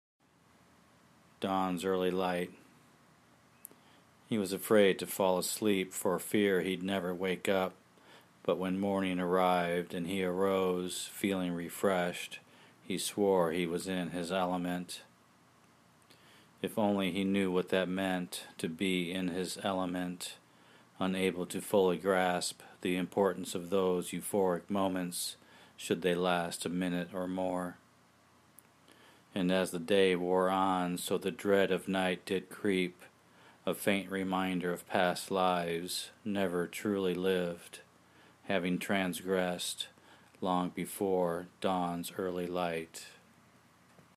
Your voice narration breathes life into the words.